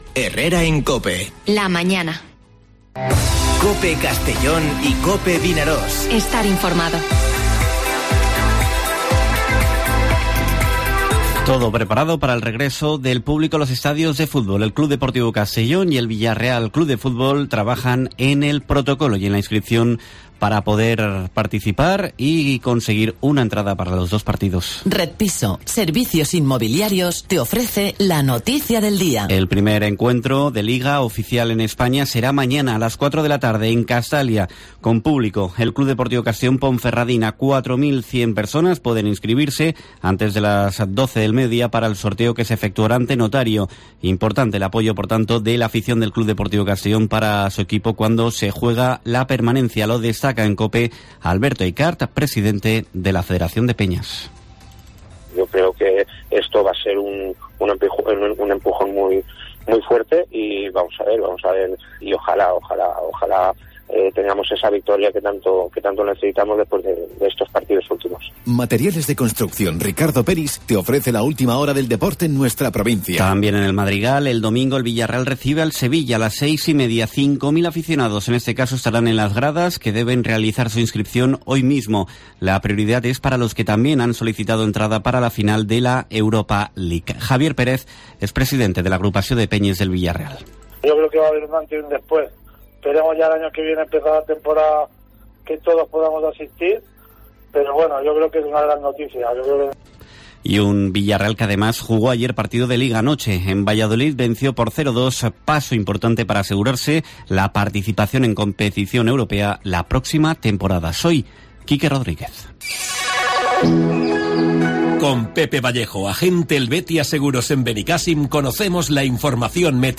Informativo Herrera en COPE en la provincia de Castellón (14/05/2021)